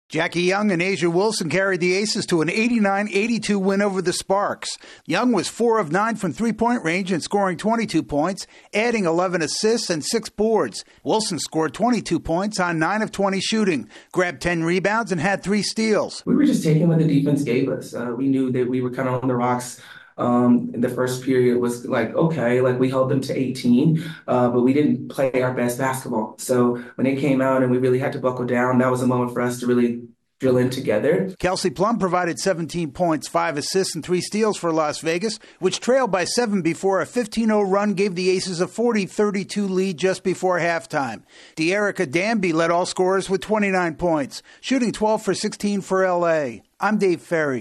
The defending WNBA champs are off to a 2-0 start. AP correspondent